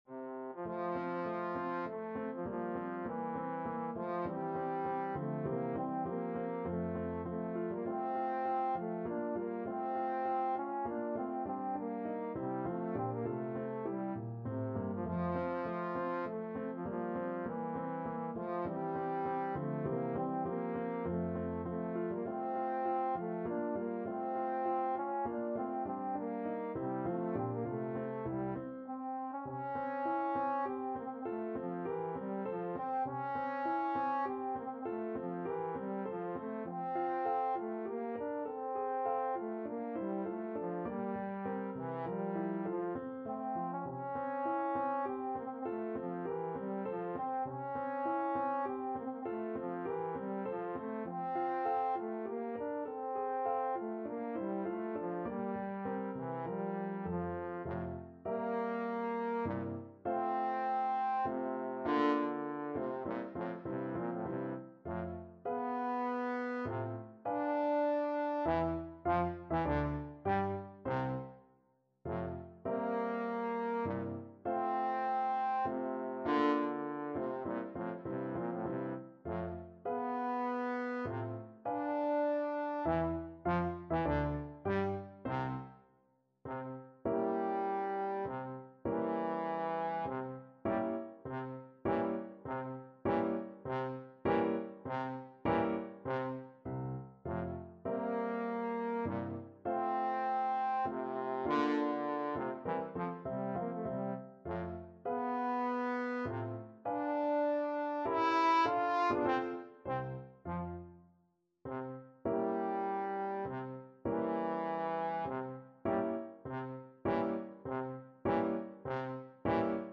3/4 (View more 3/4 Music)
Menuetto Moderato e grazioso
Trombone  (View more Intermediate Trombone Music)
Classical (View more Classical Trombone Music)